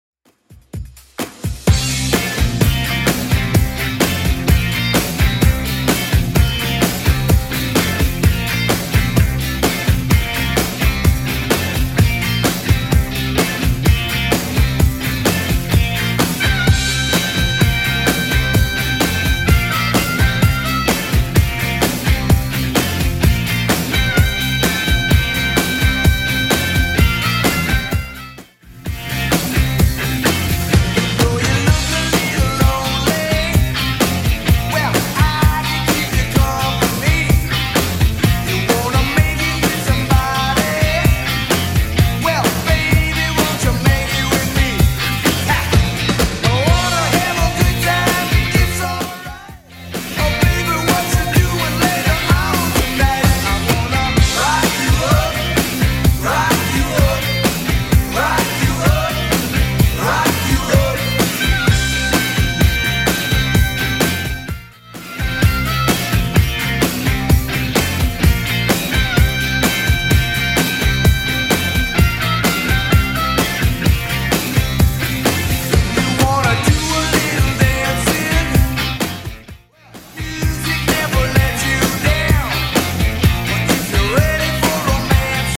Genre: 70's Version: Clean BPM: 100